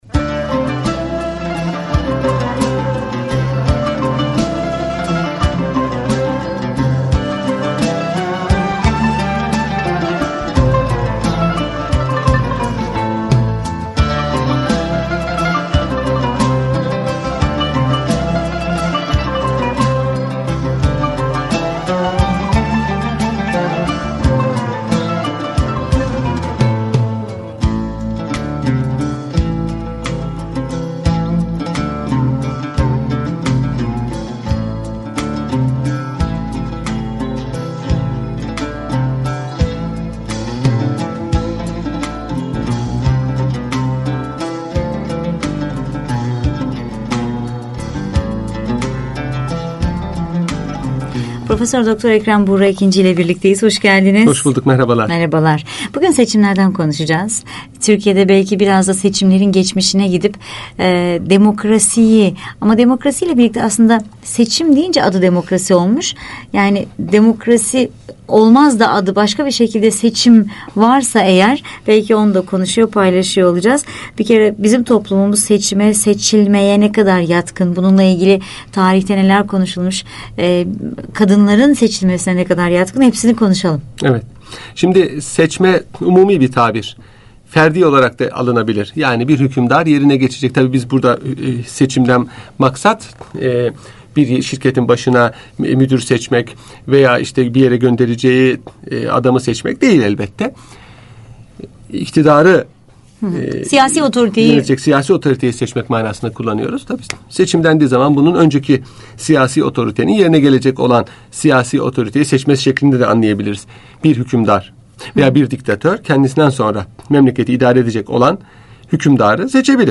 Radyo Programi - Seçimler